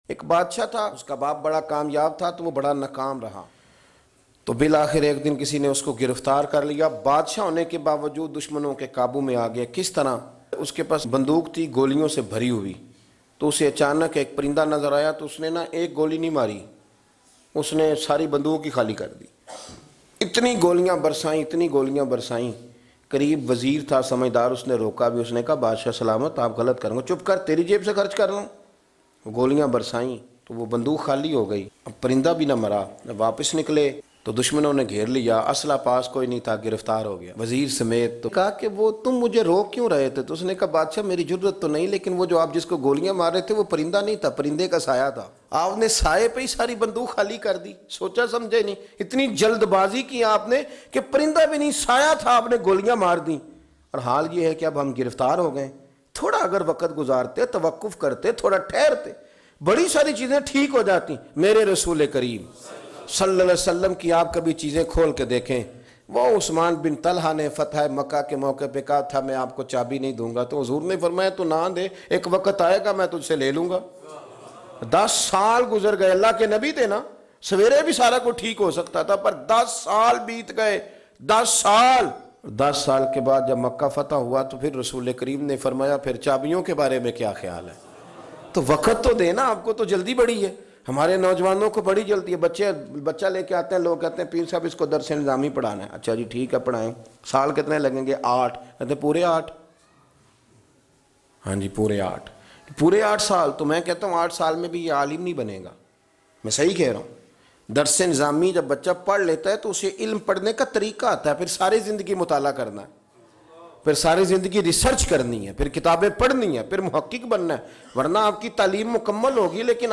Jald Baazi Insaani Zindagi Ko Tabah Kar Deti Hai Bayan MP3 Download